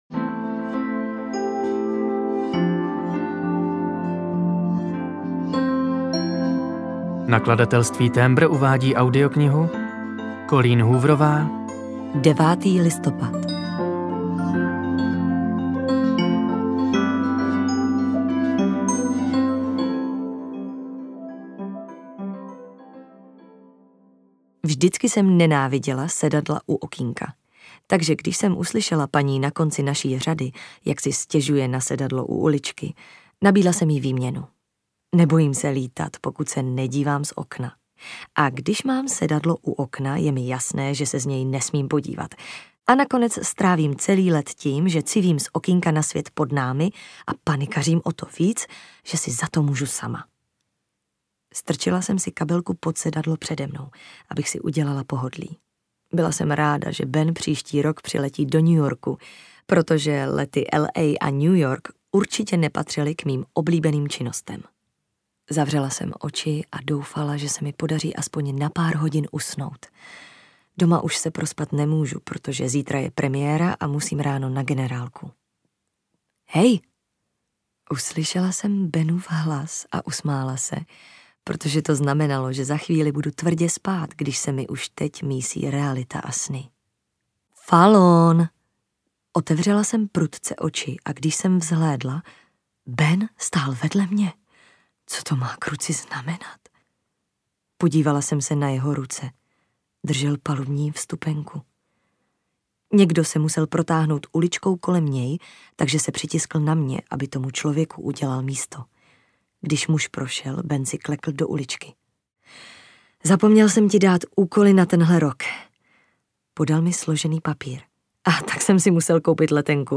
Audio kniha9. Listopad
Ukázka z knihy